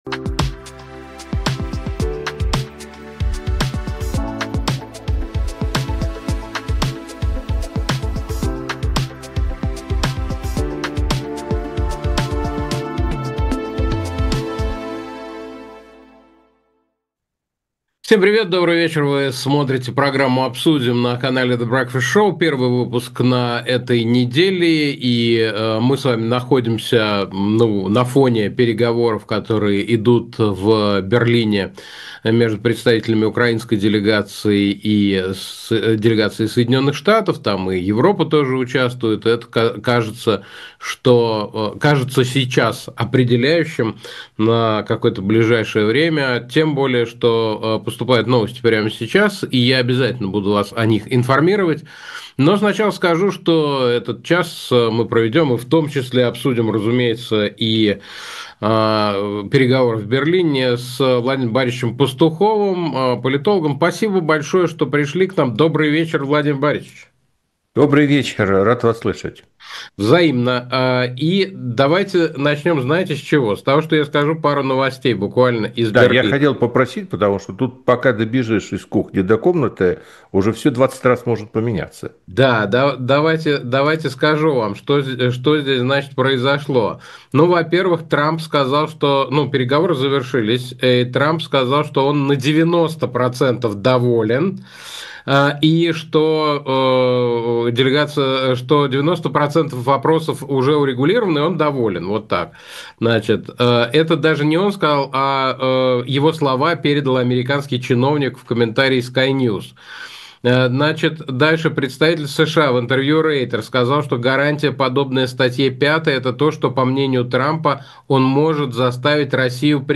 Эфир ведёт Александр Плющев